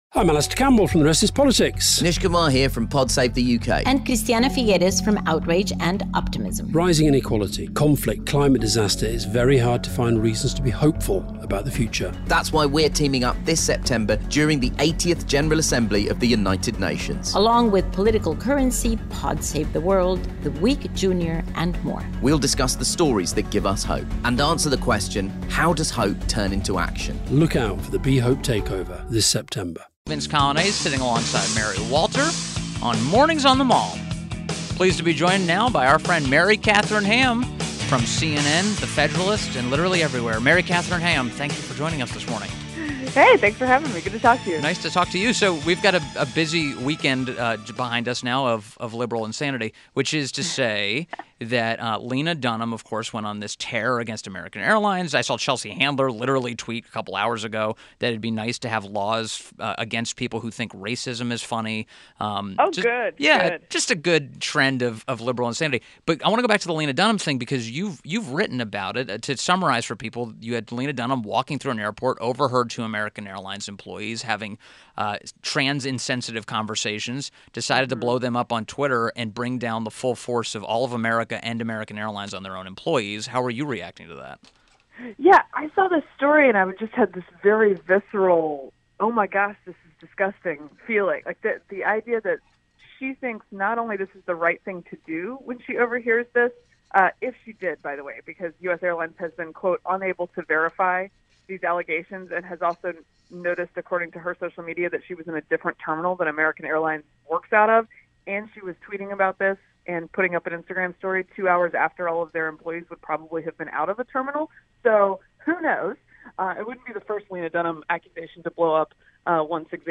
WMAL Interview - MARY KATHARINE HAM - 08.07.17
INTERVIEW – MARY KATHARINE HAM – co-author, “End of Discussion”, CNN contributor and senior writer for The Federalist and former WMAL morning show host